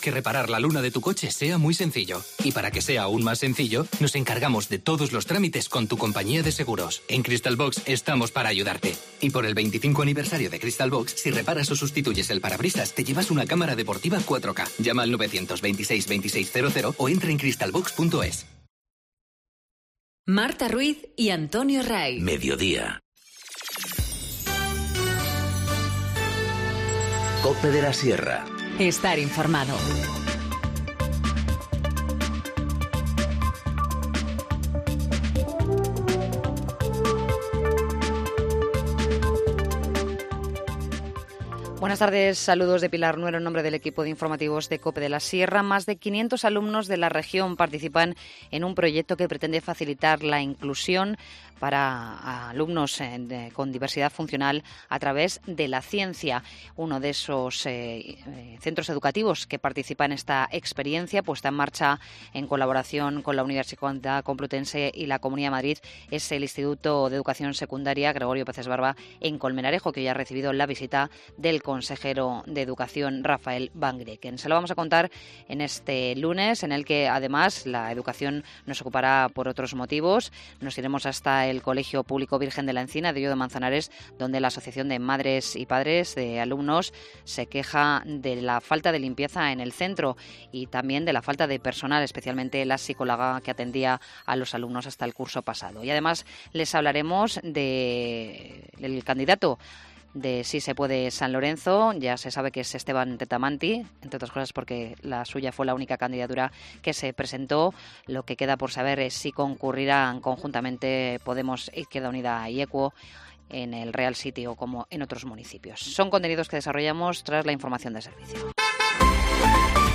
Informativo Mediodía 11 febrero- 14:20h